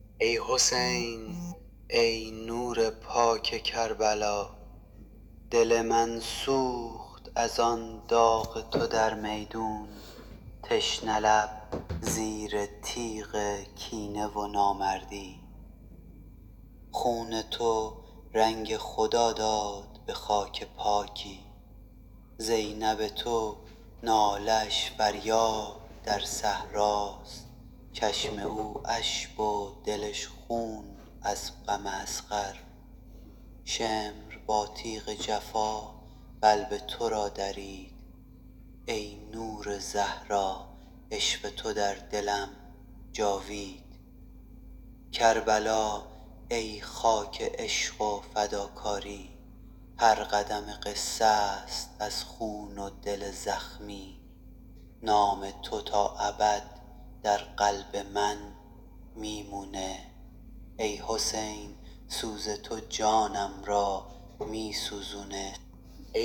با اکانت ElevenLabs می‌تونی یه صدای بم و سوزناک مردونه انتخاب کنی که حس مداحی رو منتقل کنه.
تولید نوحه با هوش مصنوعی ElevenLabs